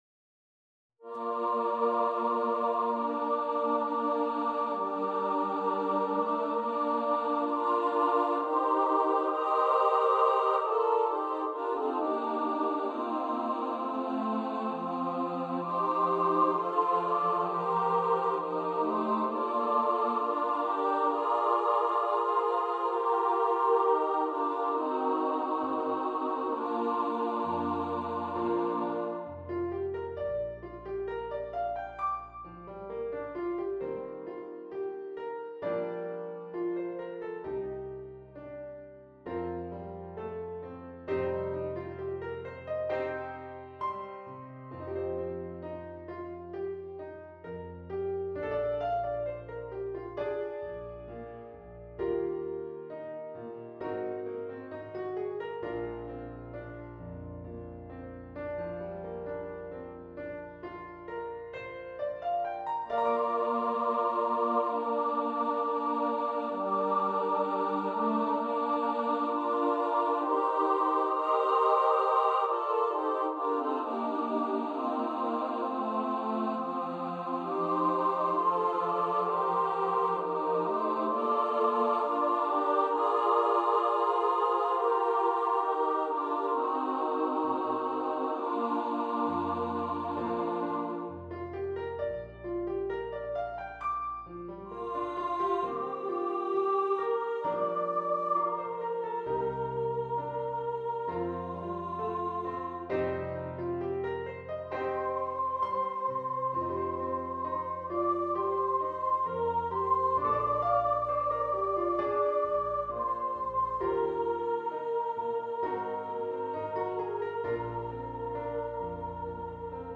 for upper voice choir and piano
Choir - 4 part upper voices